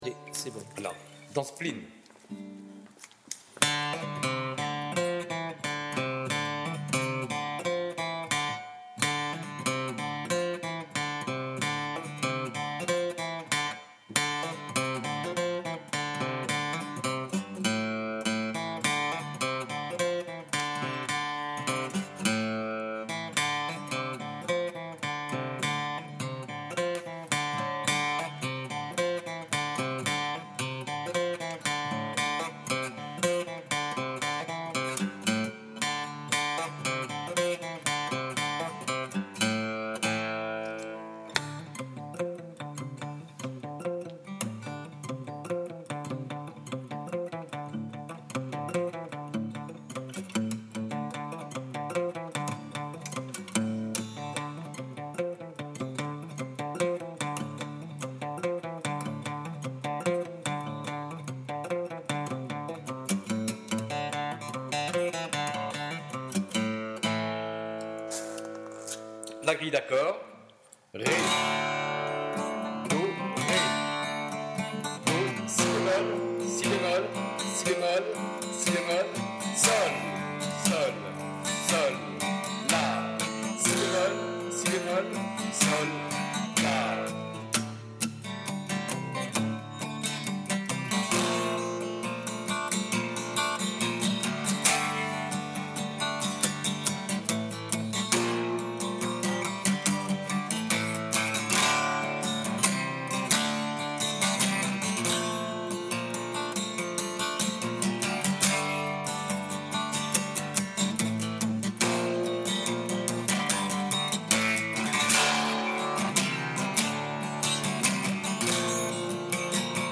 :mp3:2013:stages:guitare
plinn.mp3